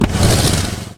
tank-engine-load-2.ogg